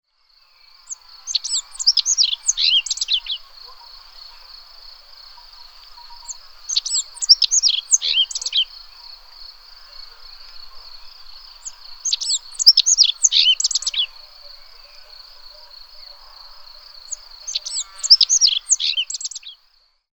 Sit negre
Dificultat Baixa Estatus Resident Habitat Alta muntanya 🎵 ESCOLTAR CANT DE L'OCELL
Emberiza-cia.mp3